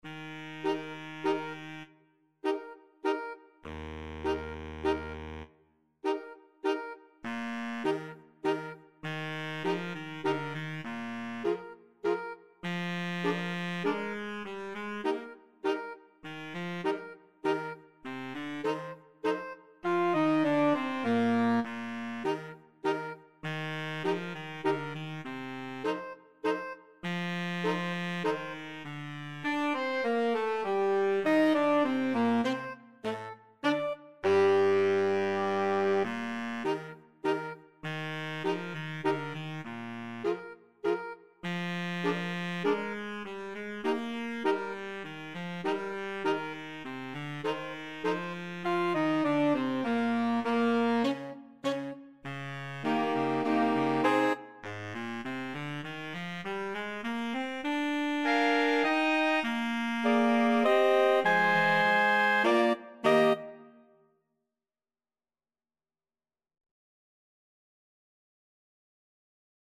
Free Sheet music for Saxophone Quartet
Soprano SaxophoneAlto SaxophoneTenor SaxophoneBaritone Saxophone
3/4 (View more 3/4 Music)
Eb major (Sounding Pitch) (View more Eb major Music for Saxophone Quartet )
Slowly = c.100